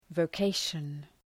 {vəʋ’keıʃən}